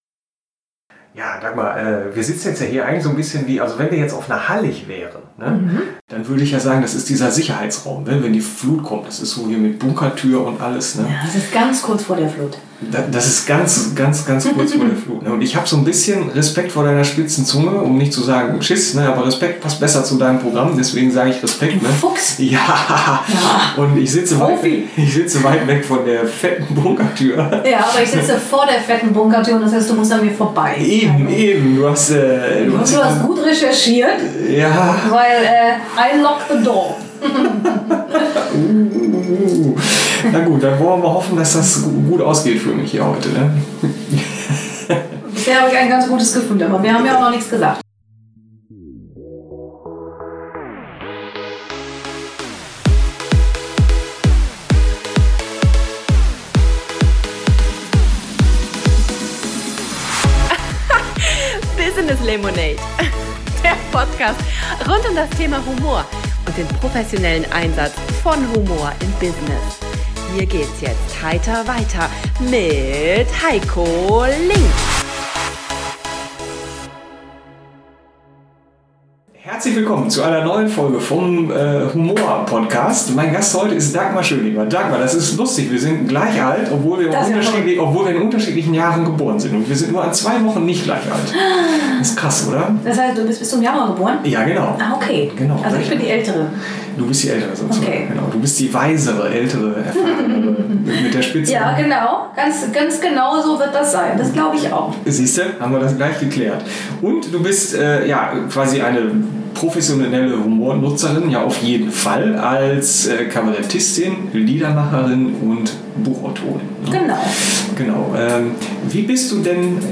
Nebenan rasselt ein vermutlich noch aus dem 2. Weltkrieg stammender Getränkekühler wie ein Schützenpanzer auf Kriegspfad. Die Bunkertür zu machen? Das trauen wir uns nicht!